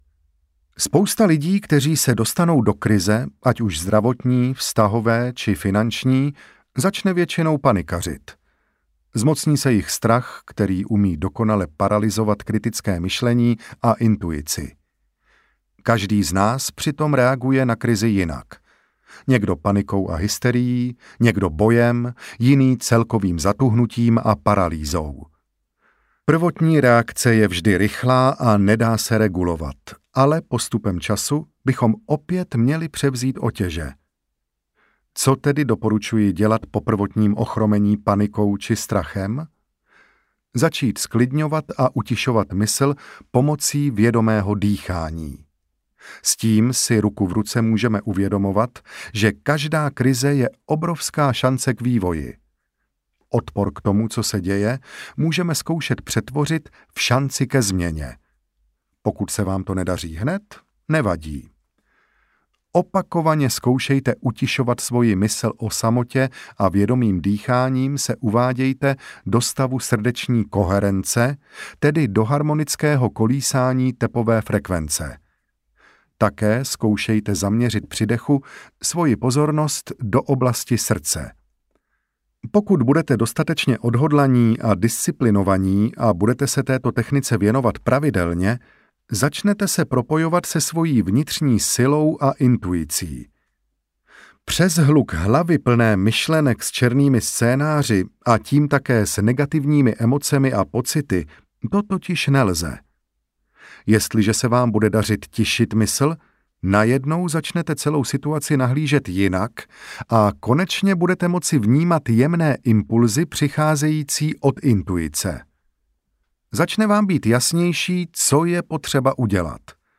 Audiokniha Rozhodni se být zdrav